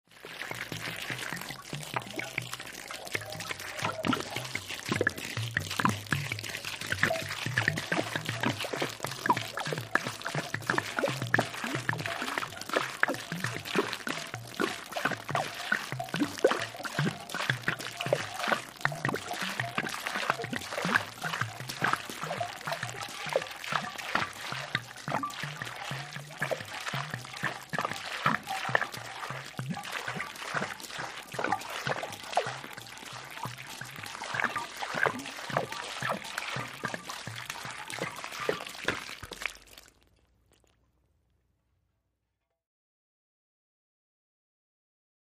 Water, Pour | Sneak On The Lot
Water Being Poured Our Of A Big Bottle Onto Dirt